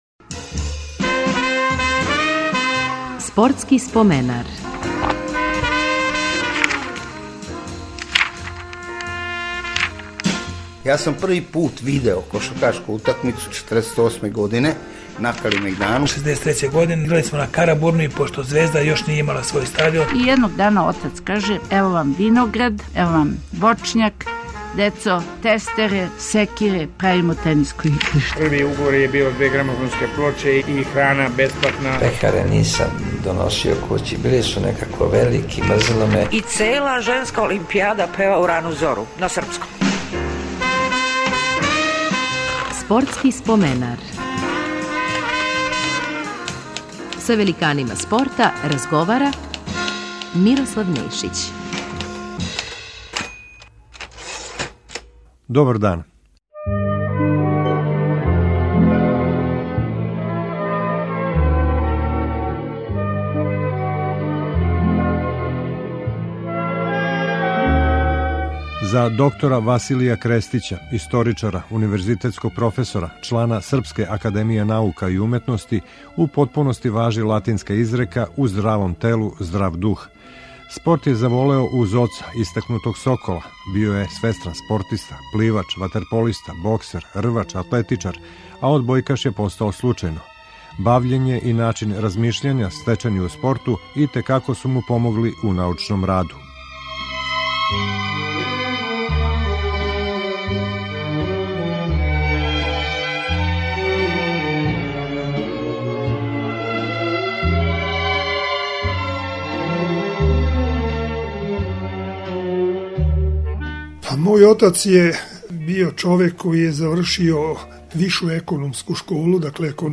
Гост ће нам бити одбојкаш, академик доктор Василије Крестић.